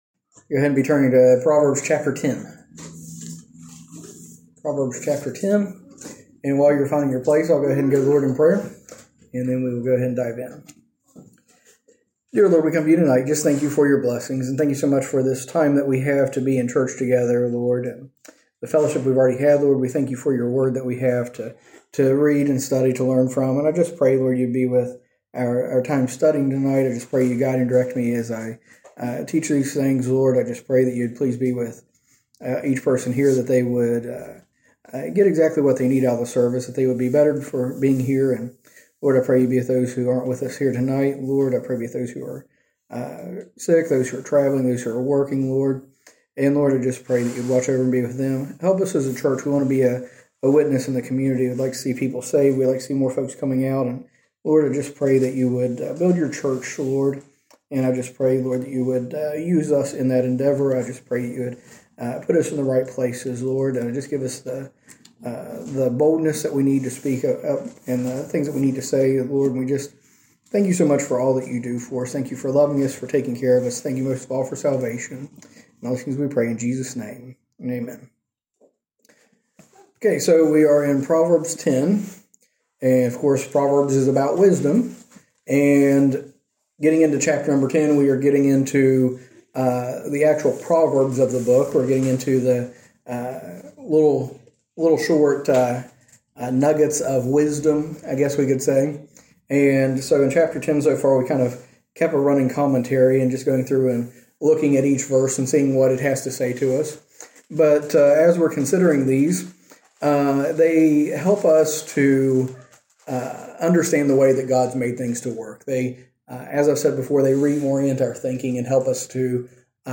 A message from the series "Proverbs."